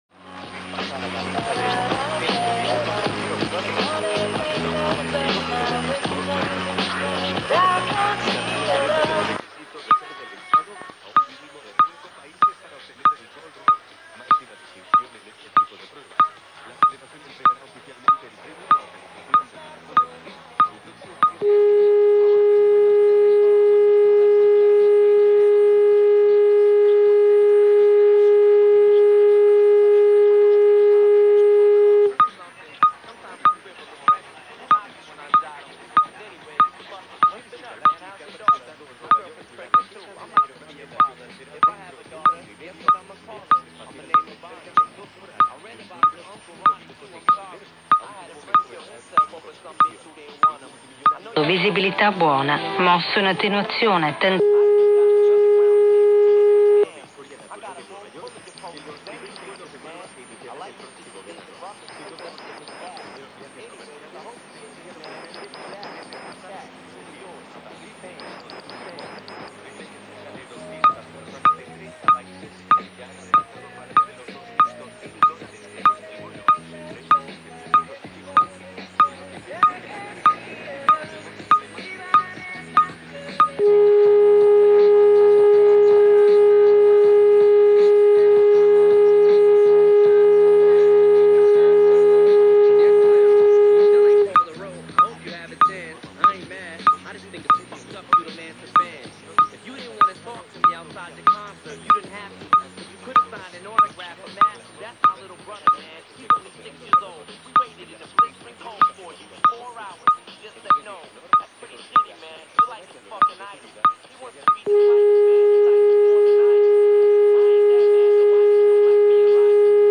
Rai radiouno inizio trasmissioni ore 6.00 onde medie coltano 657 khz.mp3